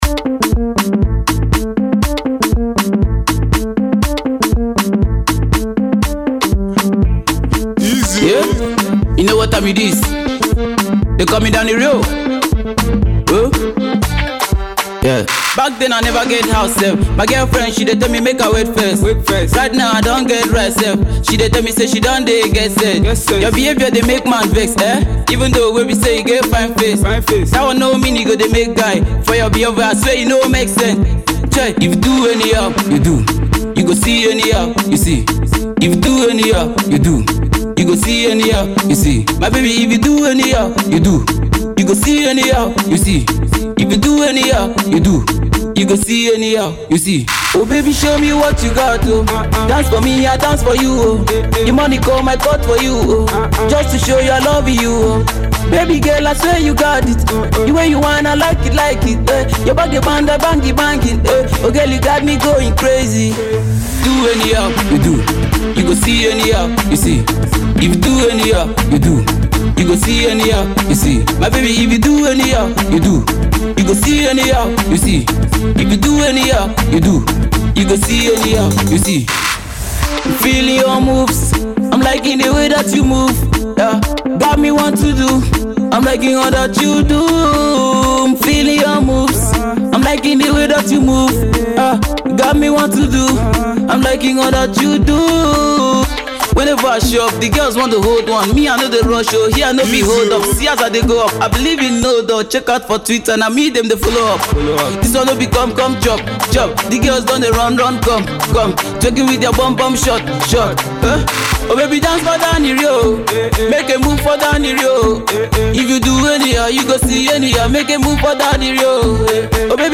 Pop
groovy refix